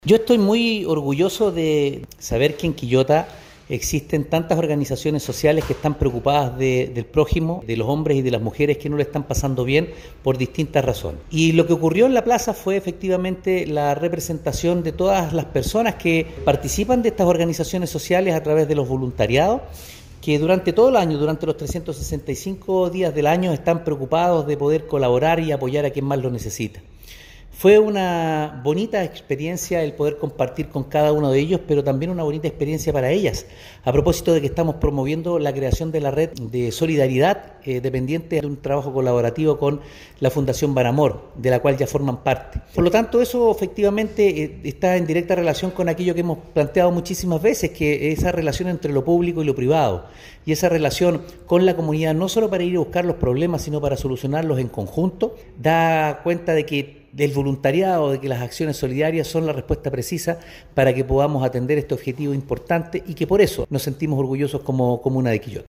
Fundación BanAmor celebró el Día Internacional del Voluntario con Feria en Plaza
Alcalde-Oscar-Calderon-Sanchez-Feria-Voluntariados-1.mp3